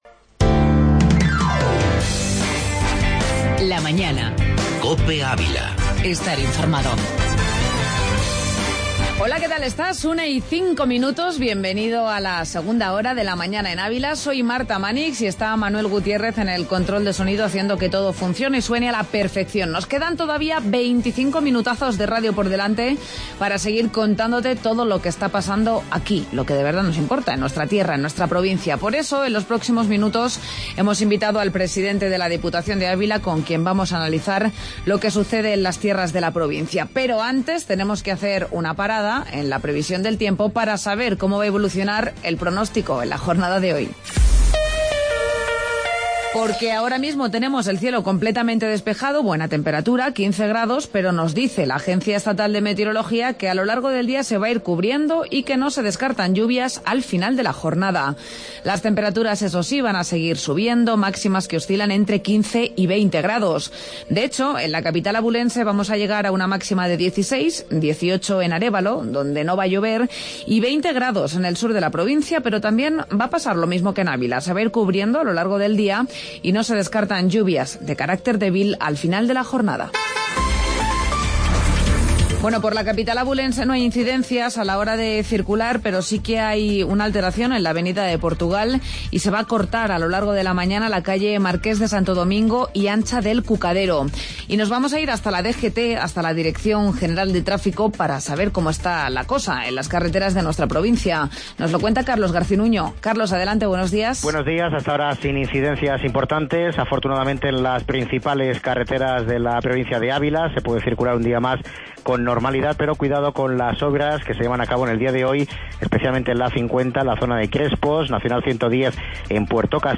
AUDIO: Entrevista Presidente de la Diputación de Avila